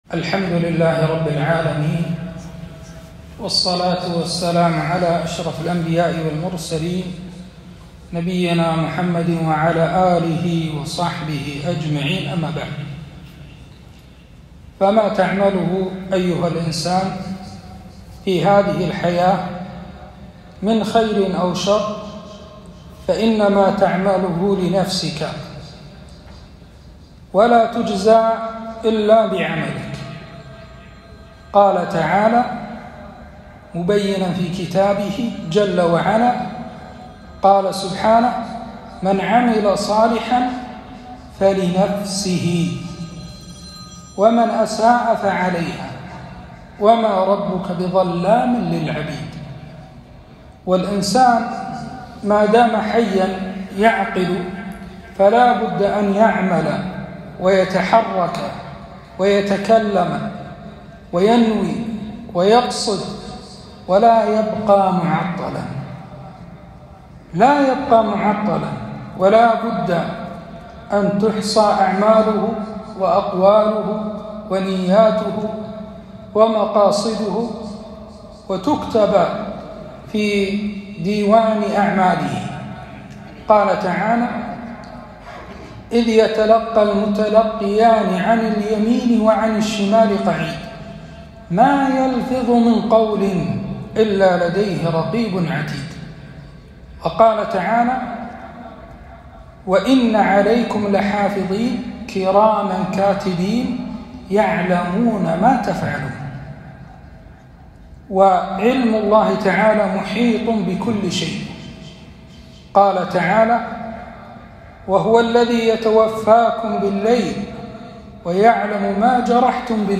كلمة - أهوال يوم القيامة